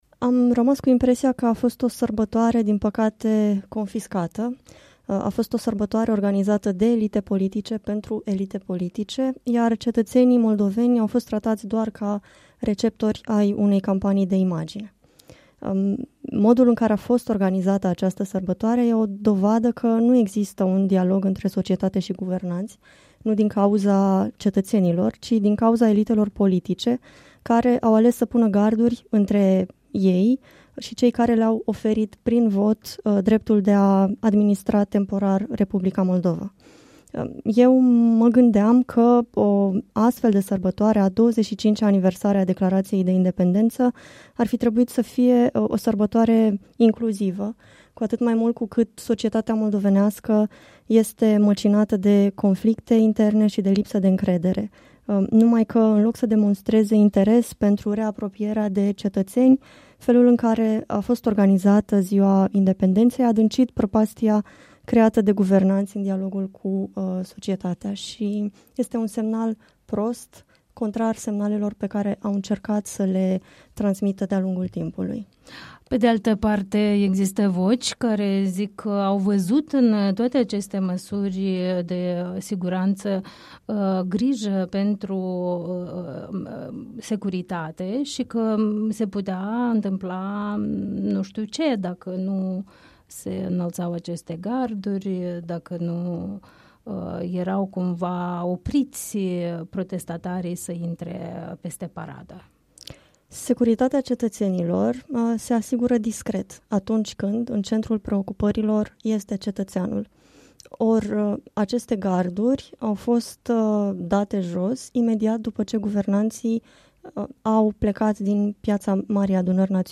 în dialog cu